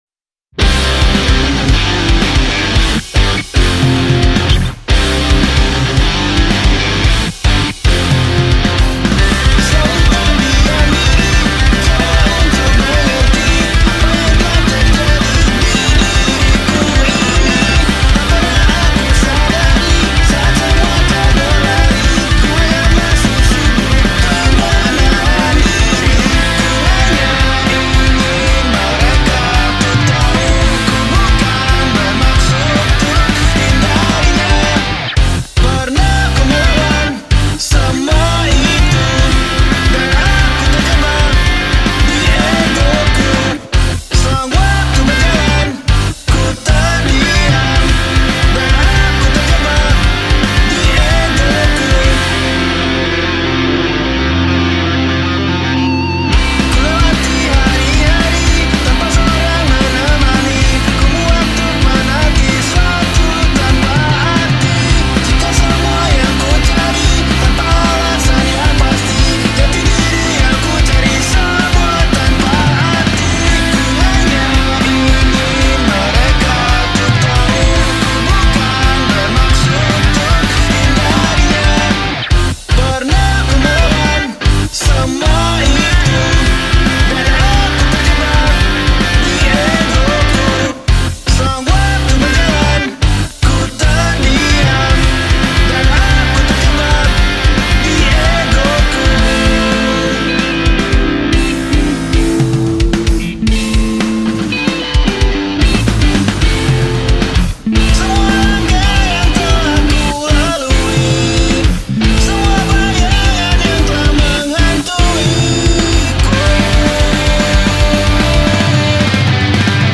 Denpasar Alternative
grub musik Pop Punk